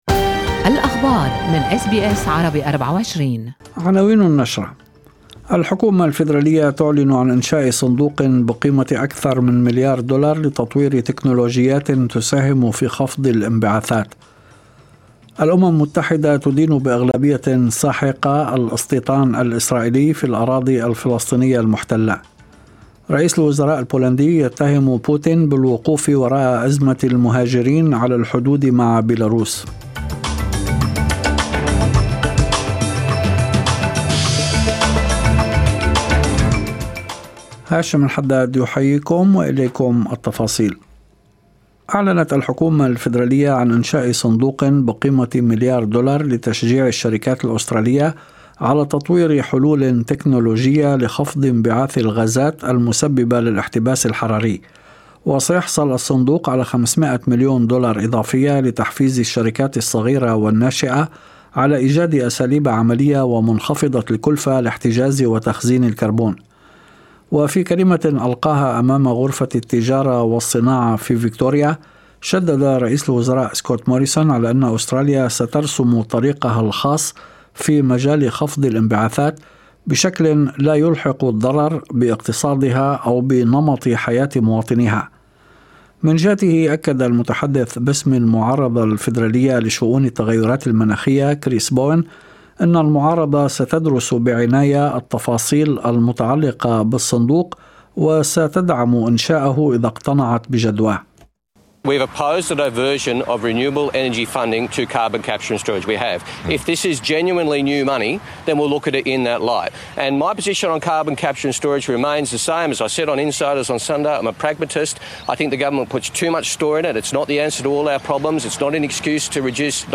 نشرة أخبار المساء10/11/2021